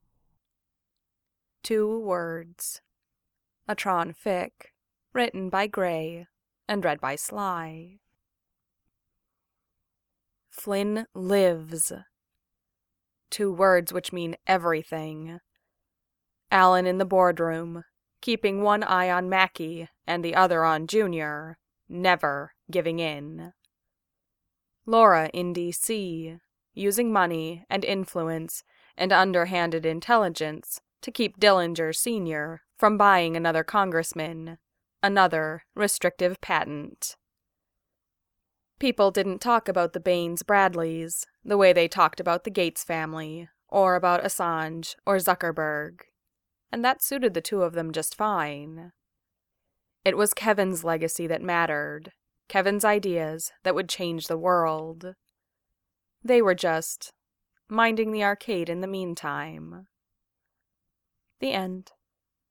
MP3 (1.04 MBs) |-|-| Audiobook (625 KBs) (Right click and "Save As")